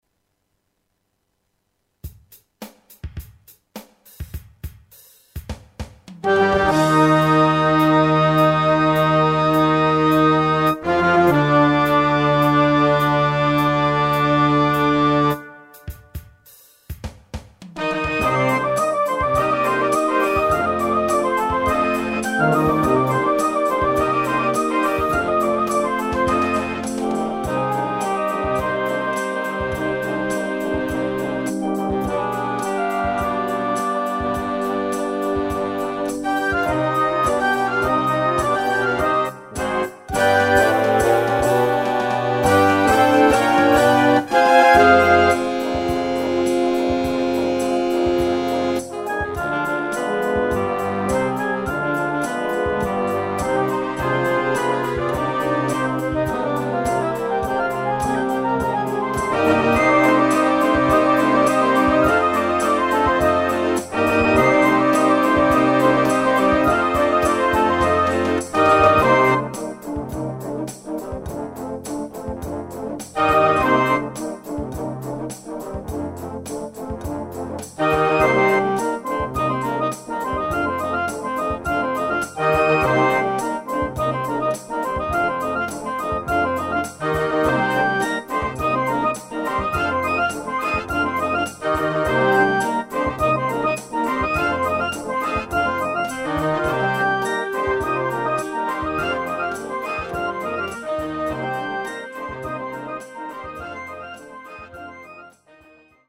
Blaasorkest
Demo